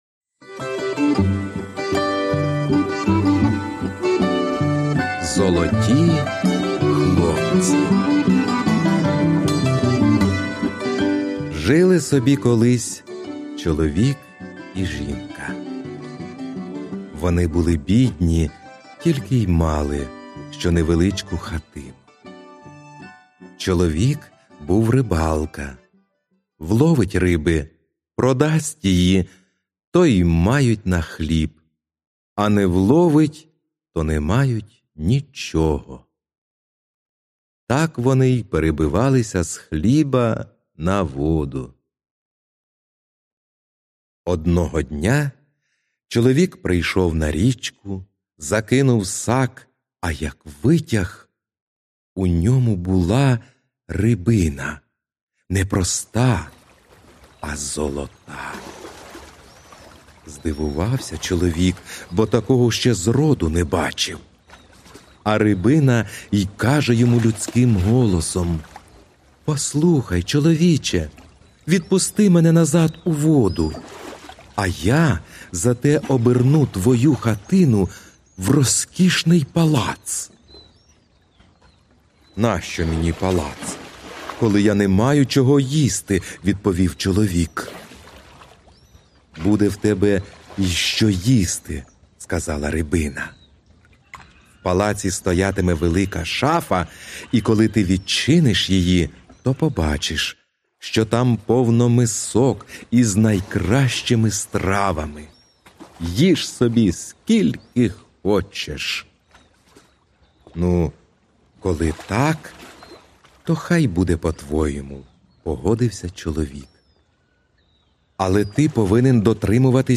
Аудіоказка Золоті хлопці